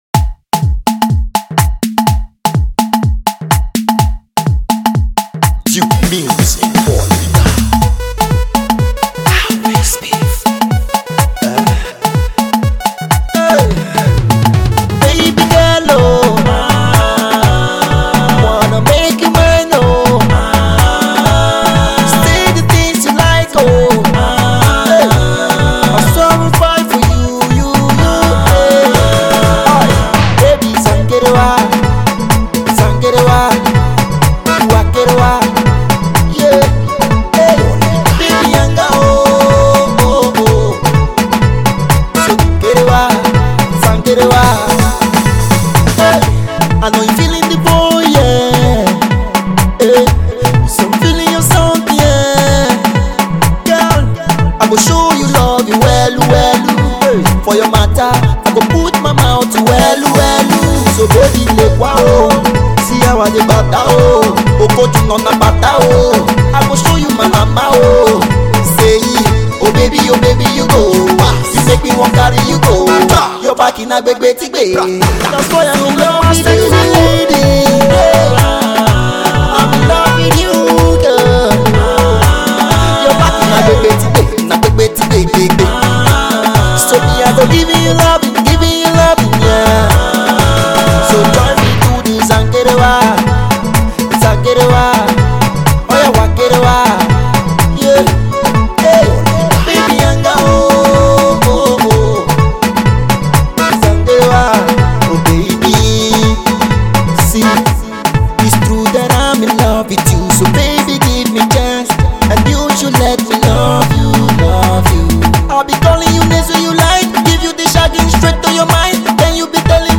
Afro tune